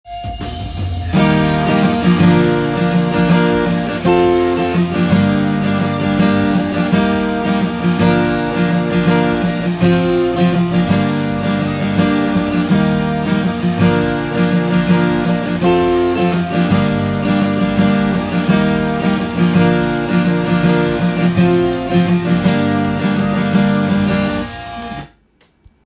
イントロストロークtab譜
音源にあわせて譜面通り弾いてみました
エモーショナルな雰囲気がする
溜めながら細かく弾くとエモ系ギターの特徴を出すことが出来ます。
３カポで弾くとtab譜のような簡単なコードで弾けます。